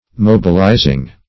Mobilize \Mob"i*lize\, v. t. [imp. & p. p. Mobilized; p. pr. &